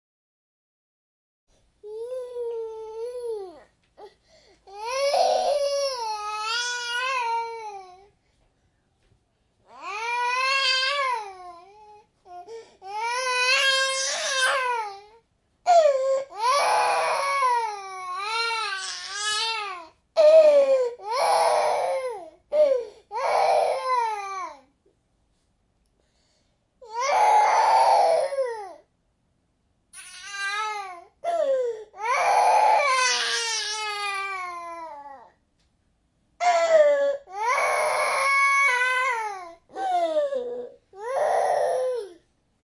Baby Cry Sound Button - Free Download & Play